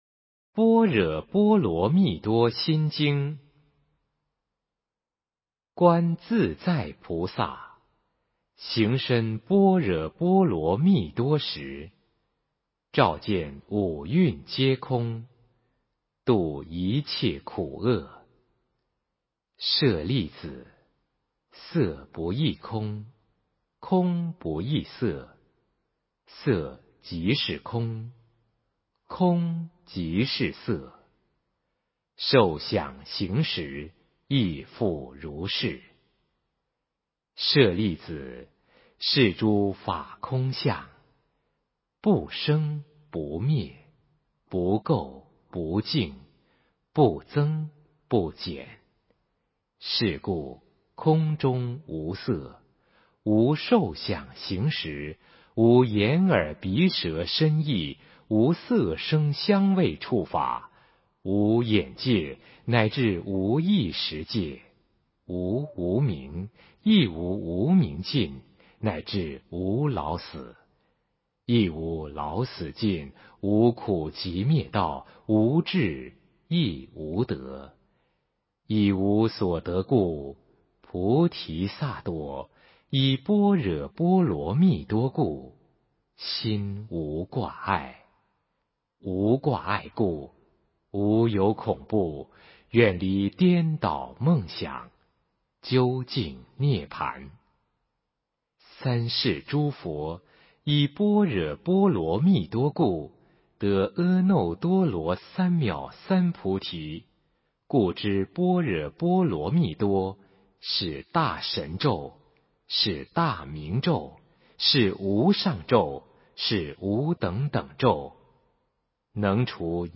心经 诵经 心经--佚名 点我： 标签: 佛音 诵经 佛教音乐 返回列表 上一篇： 金刚经 下一篇： 觉悟之路38 相关文章 阿弥陀佛（古筝）--未知 阿弥陀佛（古筝）--未知...